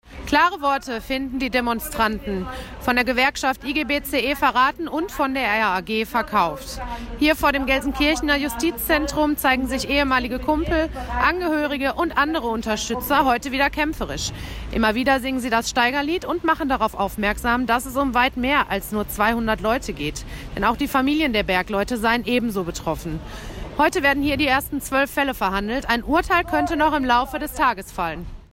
Vor dem Justizzentrum in Gelsenkirchen-Ückendorf protestieren gerade knapp 100 ehemalige Bergleute und deren Familien.
protest-der-bergleute-vor-dem-gelsenkirchener-arbeitsgericht.mp3